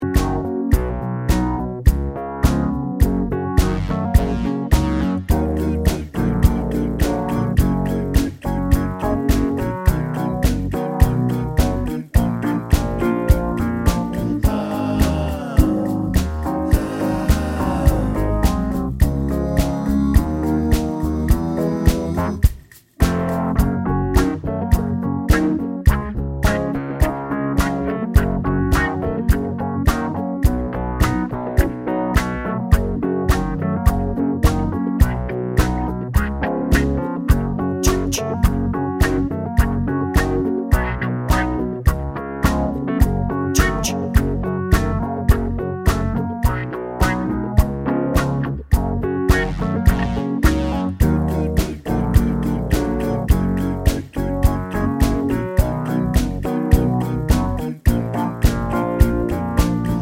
One Semitone Down Cut Down Pop (1970s) 2:35 Buy £1.50